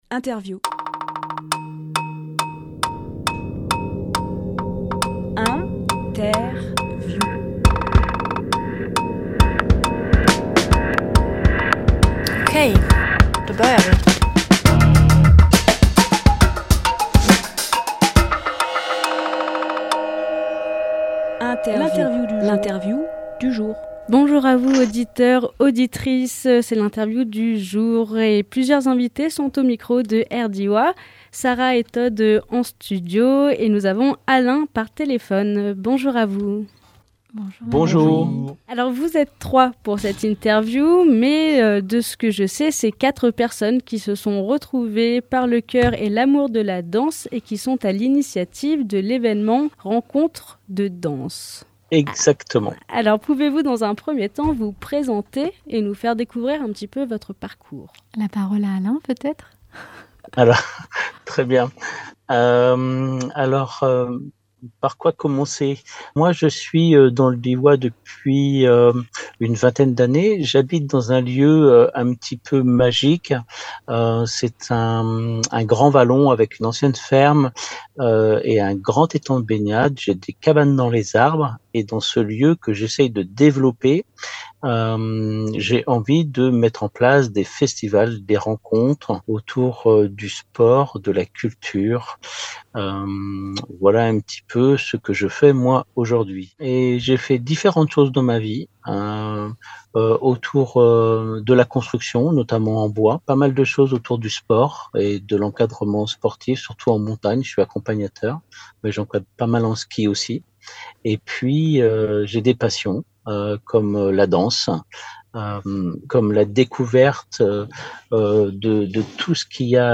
Emission - Interview Rencontres de Danse Publié le 11 septembre 2023 Partager sur…